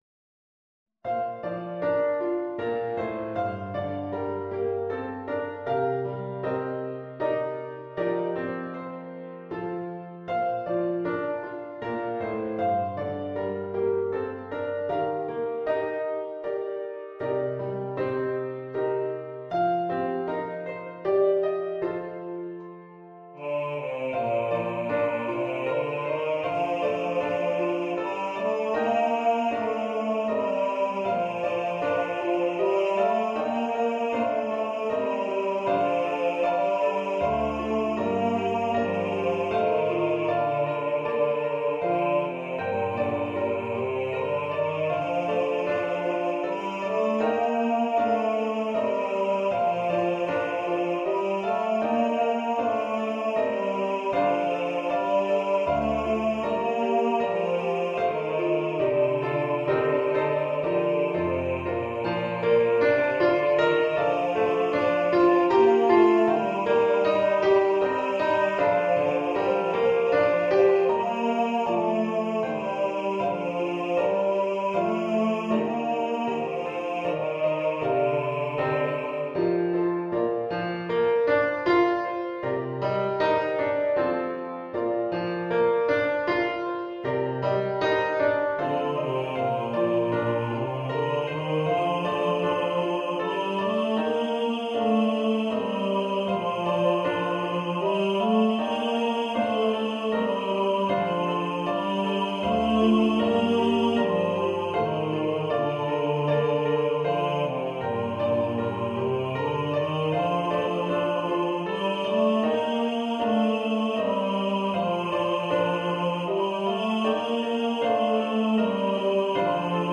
engl. Weihnachtslied